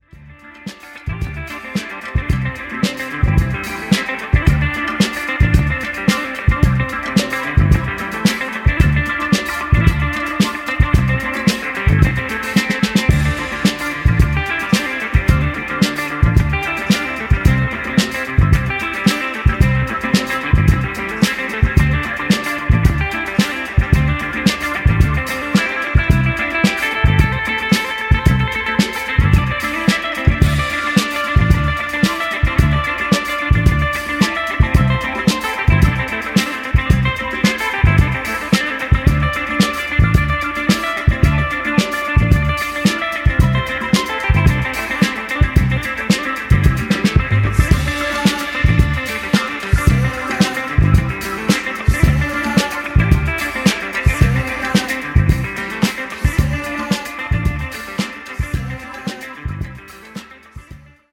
ten entirely new renditions replayed and remade in 2025